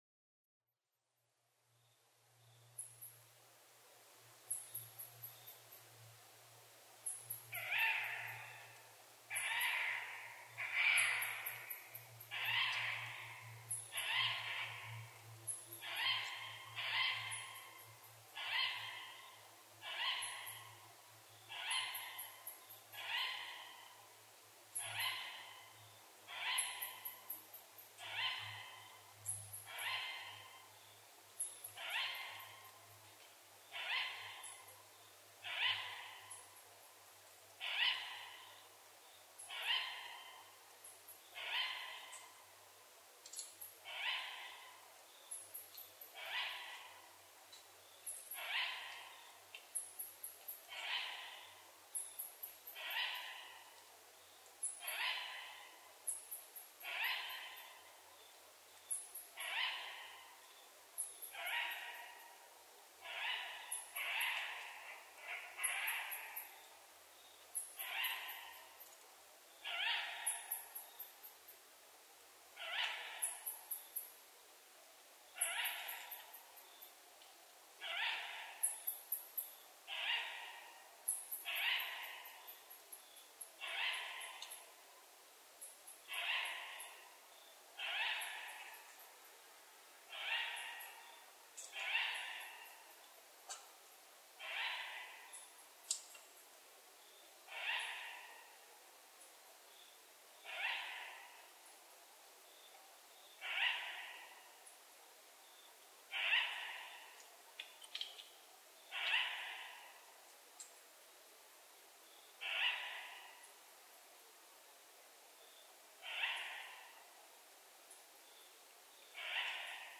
フクロウ　Strix uralensisフクロウ科
栃木県塩谷町和仁沢　alt=780m
MPEG Audio Layer3 FILE 128K 　3'15''Rec: SONY PCM-D50
Mic: Panasonic WM-61A  Binaural Souce with Dummy Head
ウギャーと鳴き続けます。落葉樹に囲まれた涸沢 他の自然音：　コウモリsp.・ニホンジカ